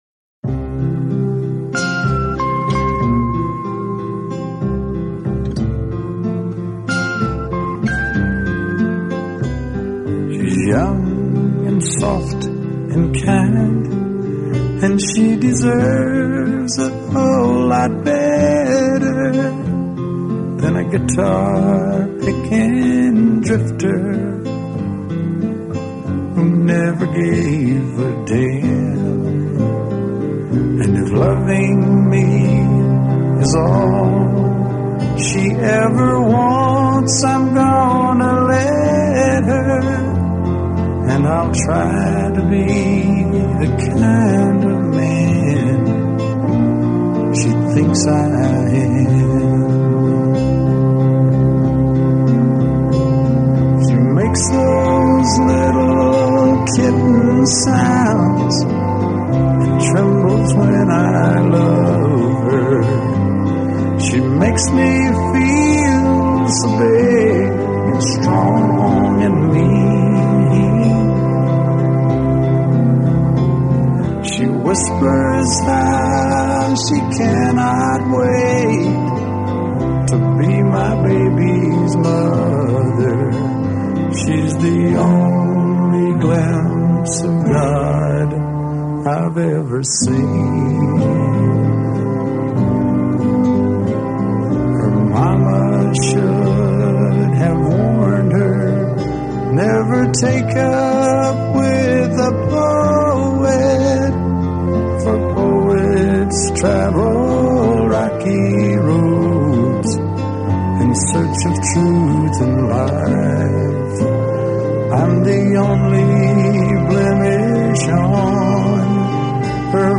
【乡村歌曲】